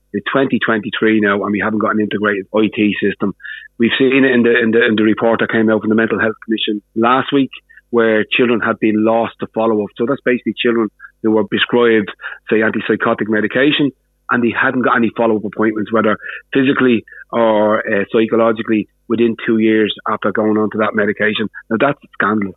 Among the measures party spokesperson for mental health, Mark Ward, is calling for is an integrated IT system: